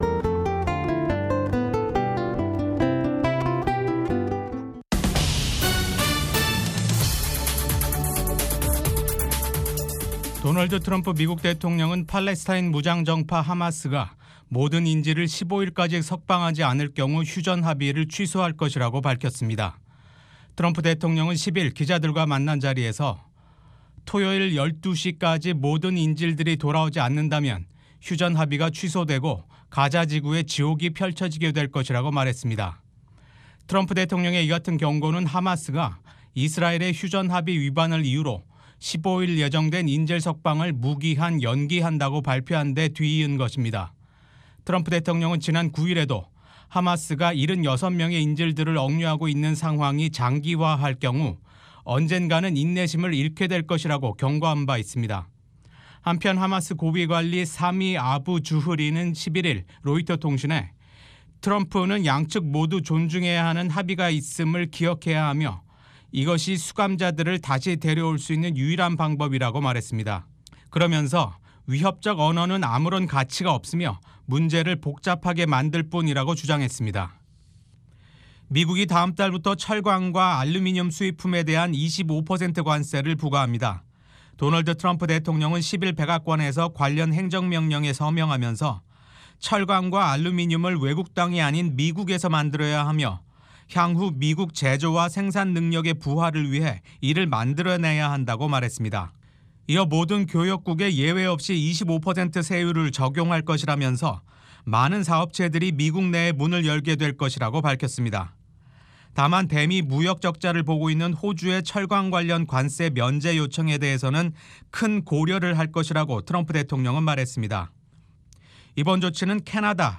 VOA 한국어 방송의 아침 뉴스 프로그램 입니다. 한반도 뉴스와 함께 밤 사이 미국과 세계 곳곳에서 일어난 생생한 소식을 빠르고 정확하게 전해드립니다.